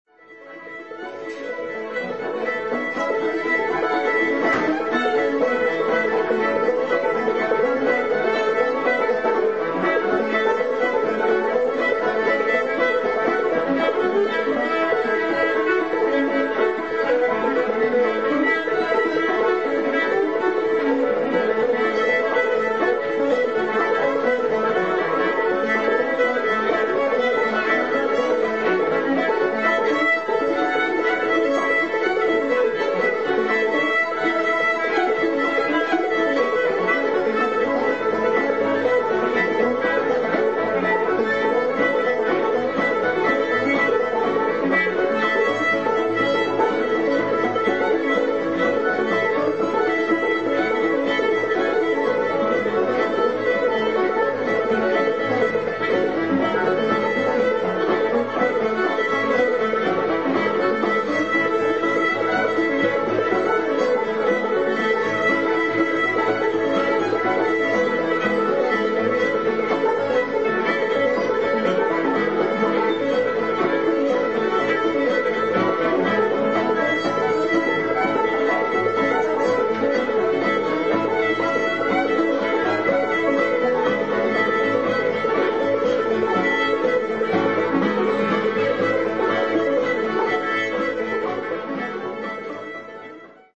Fiddle
Guitar